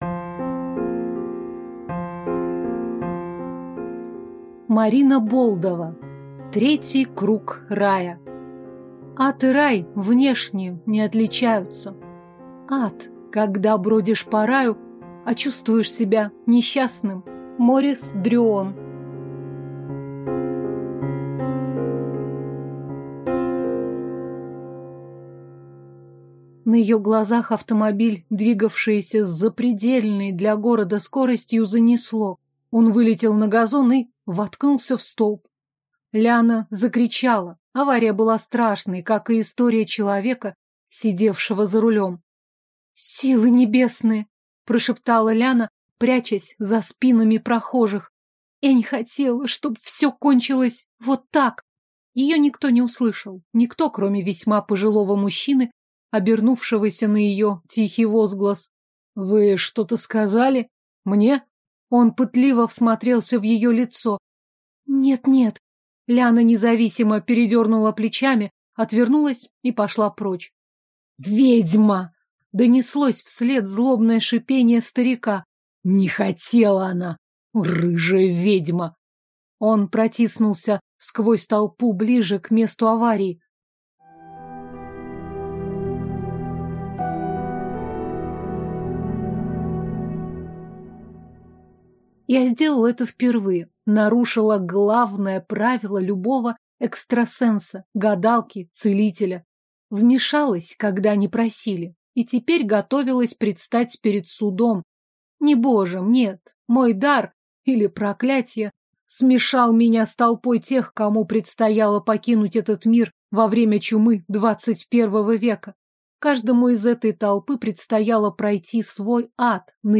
Аудиокнига Третий круг рая | Библиотека аудиокниг